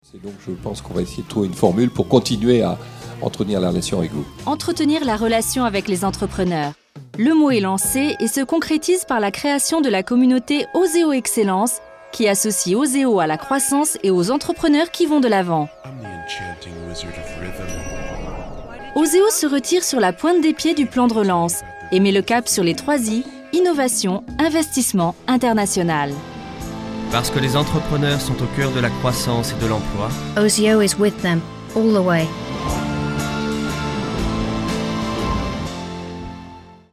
Découvrez une voix féminine aux timbres multiples : douce et élégante pour les documentaires et narrations, pleine d’énergie et de sourire pour les utilisations commerciales, libre et fantaisiste pour créer les personnages de cartoon dont vous rêvez !
Sprechprobe: Industrie (Muttersprache):
I have a versatile voice, happy, full of energy and fun for commercials, soft and elegant for corporate and documentaries, and I love creating imaginary characters.